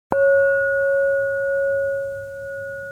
Inferno - A 3D spatial audio game.
bell_veryclose.ogg